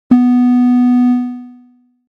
SE（ブザー）
ブー。プー。